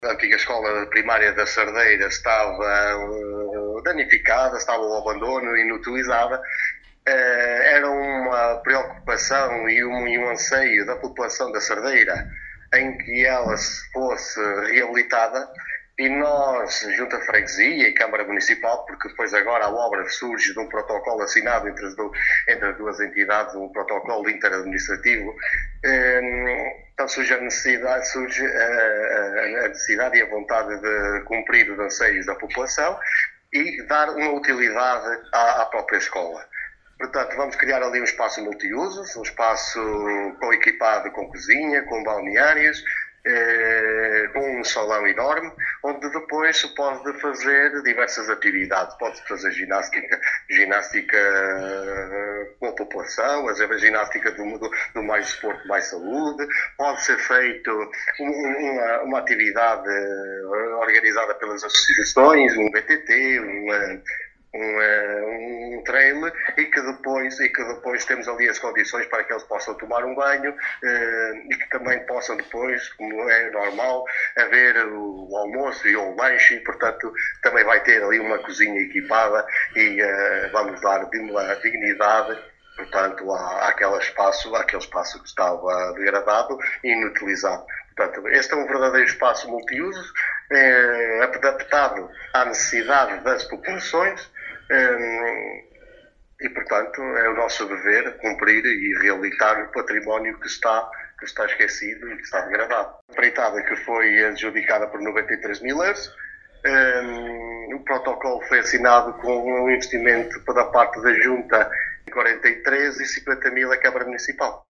Mário Morgado, Presidente da Junta de Freguesia, em declarações à Alive FM, disse esta obra vem dar resposta aos anseios da população e dar utilidade a um espaço que já há muito tempo se encontrava inutilizado, “um espaço multiusos adaptado às necessidades da população…”.